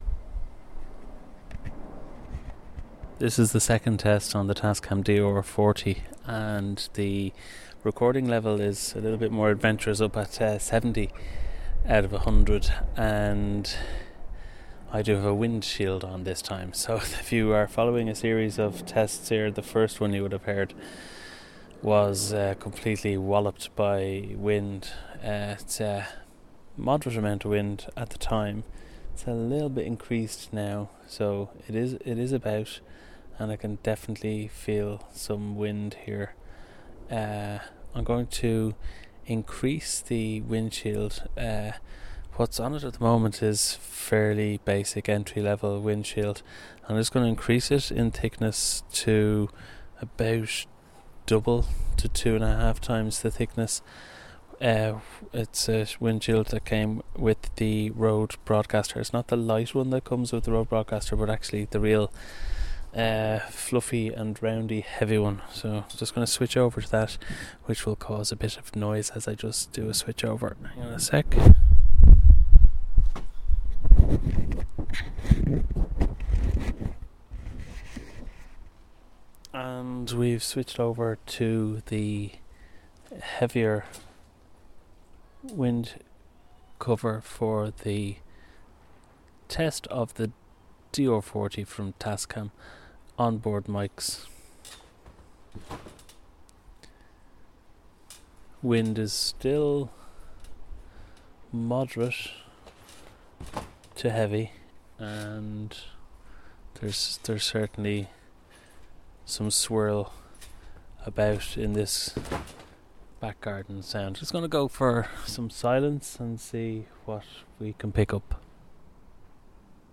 DR40 test with windshields